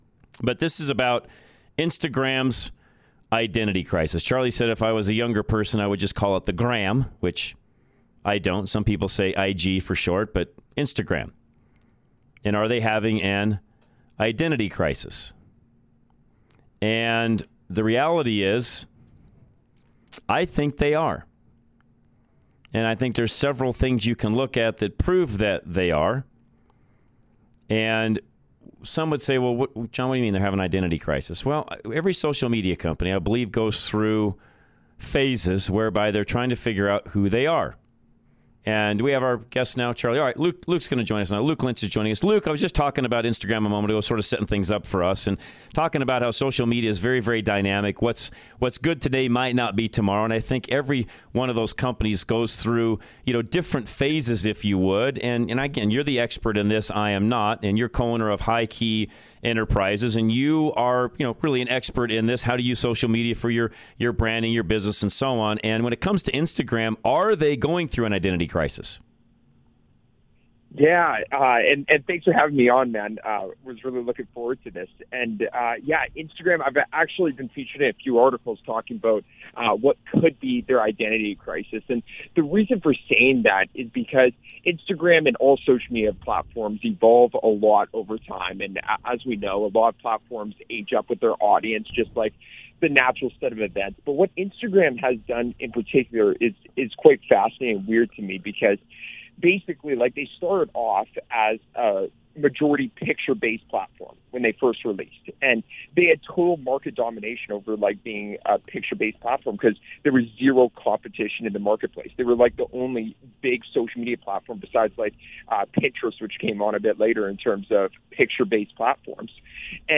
Interviews Instagram's Identity Crisis Sep 20 2022